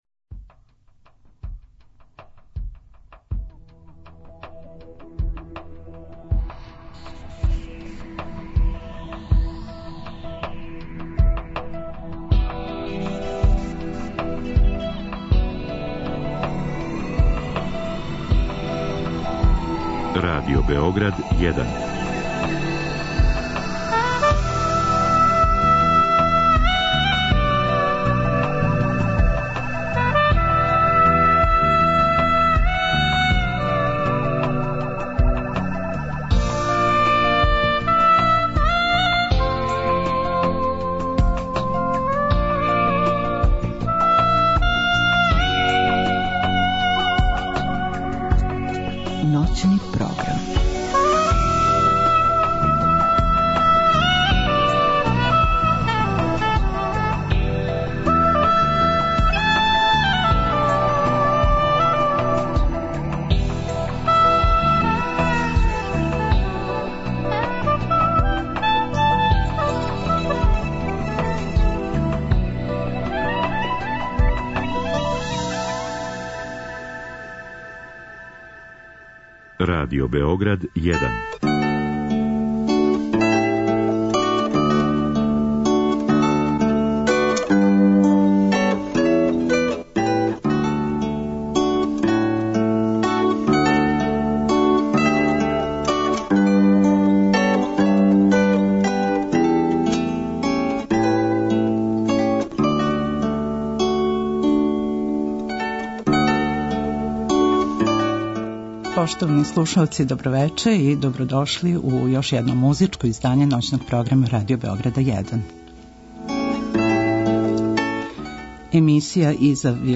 У другом делу емисије представићемо занимљиве музичке догађаје и уметнике које смо интервјуисали.